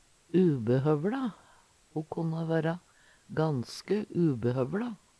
ubehøvLa - Numedalsmål (en-US)